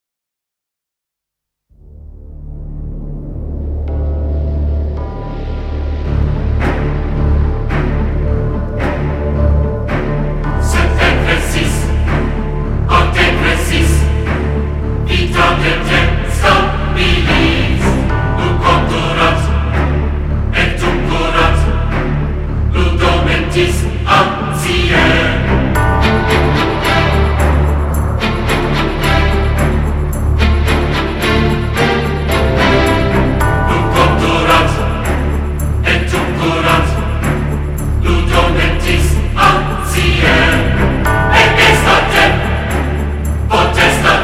原碟按7.1DTS-HD家庭影院标准制作，